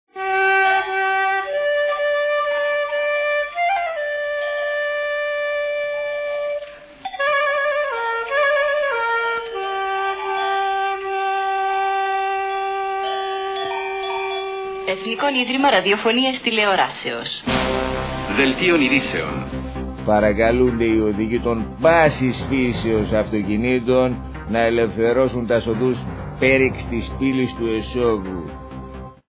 Ηχητική μπάντα παράστασης
sound 29'', track 12, ηχητικά εφέ (σήμα σταθμού και δελτίο ειδήσεων)